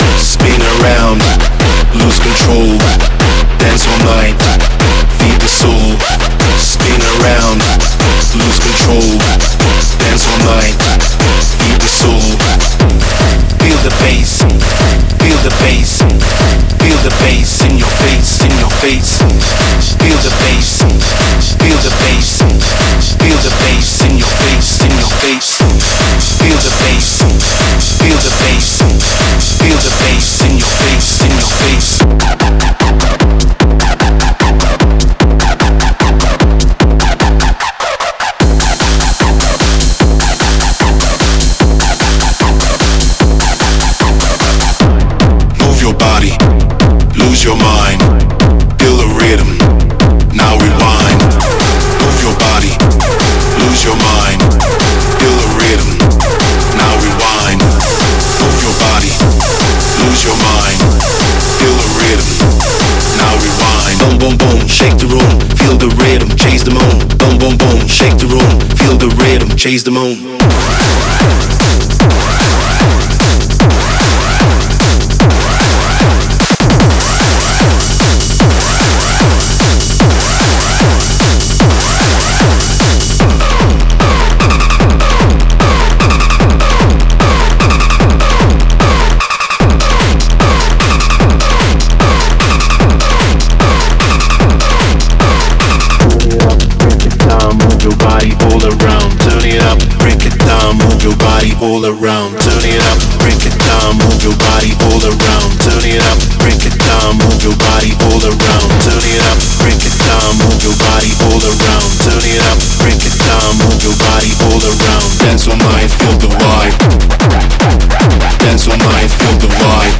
すべてのサウンドは最初から制作され、現代的なテクノサウンドを確実に提供します。
デモサウンドはコチラ↓
Genre:Hard Dance